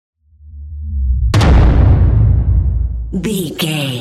Dramatic hit explosion trailer
Sound Effects
heavy
intense
dark
aggressive
hits